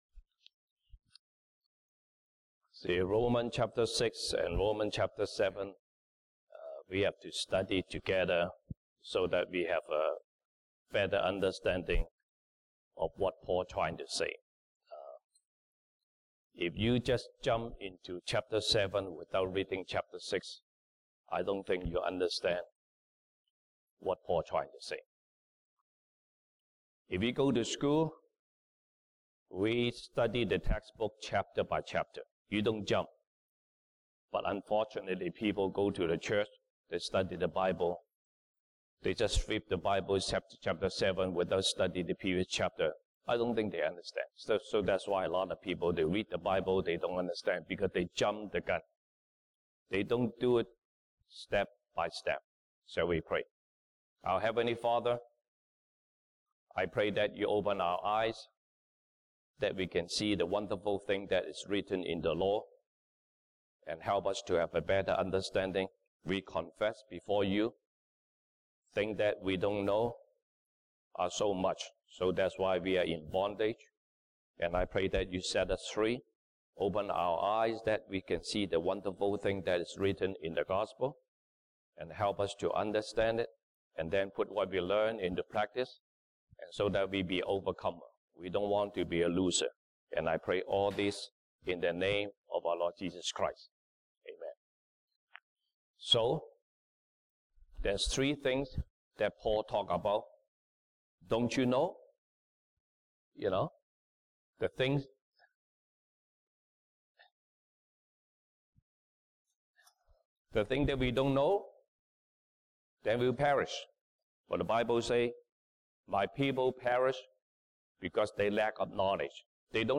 西堂證道 (英語) Sunday Service English: How can we reign in life?